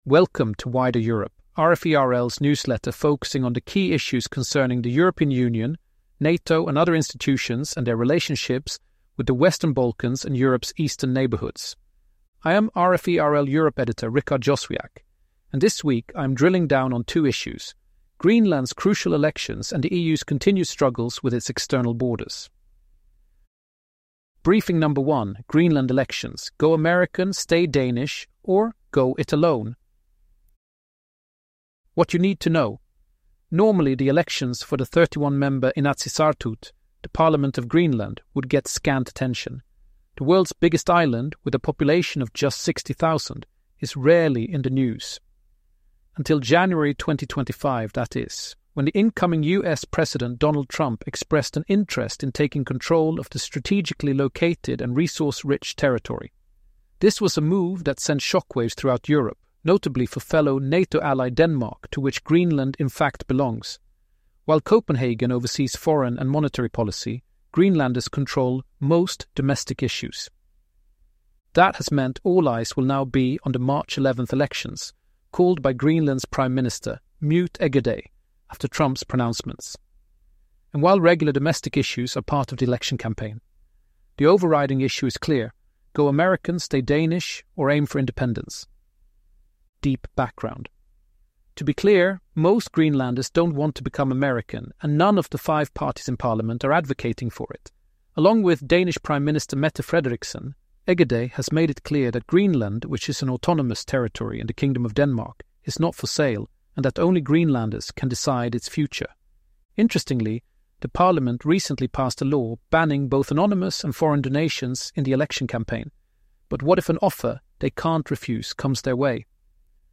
Briefing #1: Greenland Elections: Go American, Stay Danish, Or Go It Alone?
Briefing #2: EU Delays New Entry/Exit System